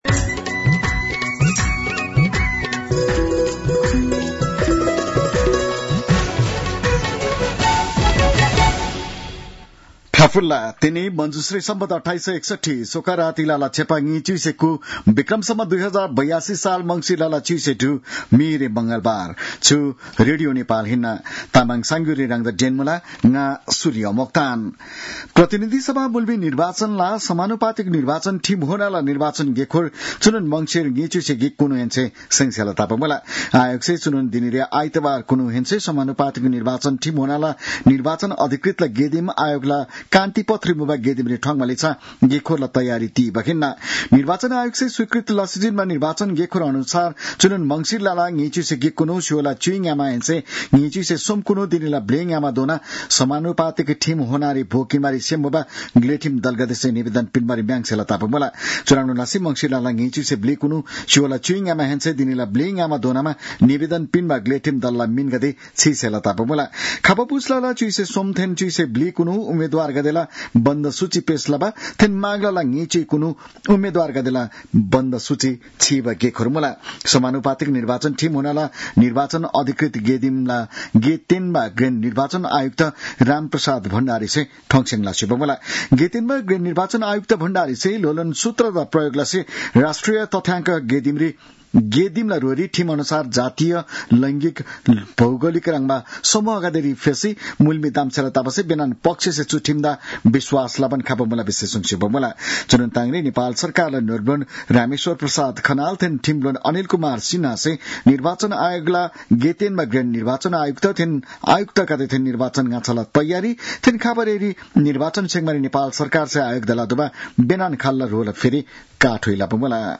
तामाङ भाषाको समाचार : १६ मंसिर , २०८२